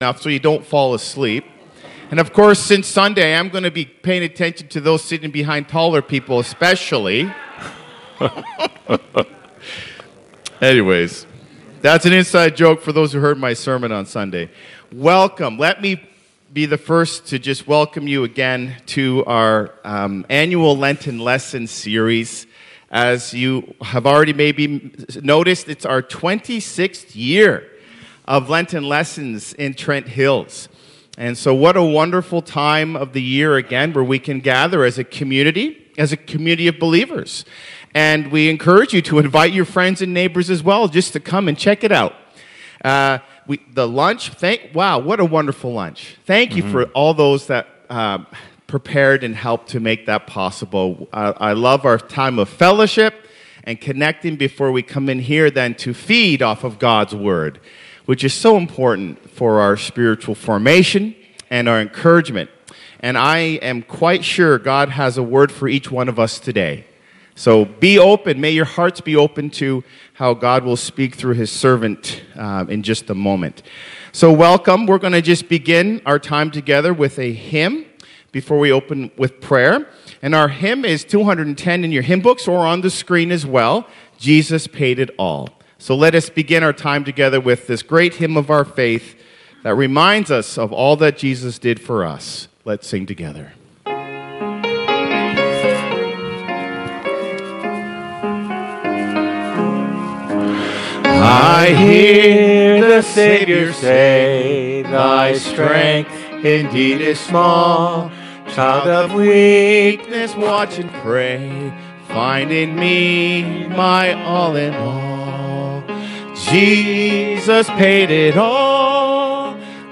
Video Sermons - Campbellford Baptist Church Inc.